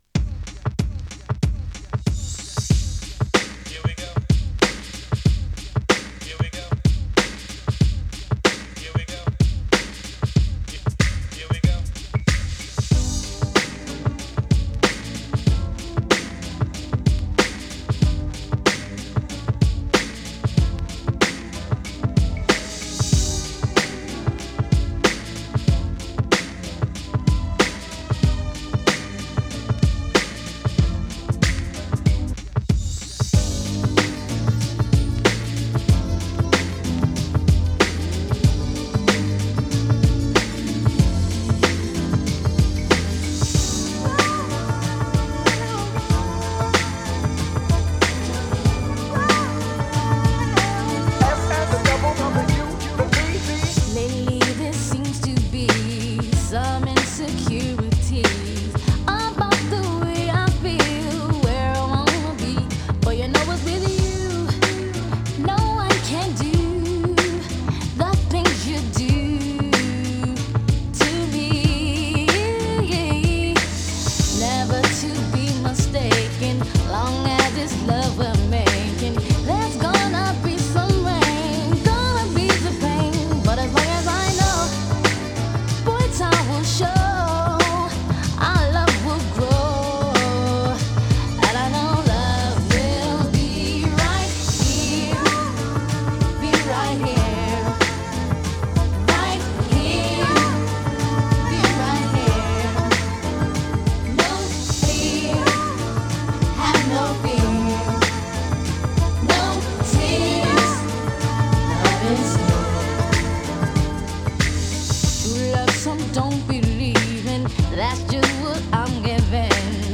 90s RnB
90sに活躍したガールズ・トリオによる初期のヒット曲。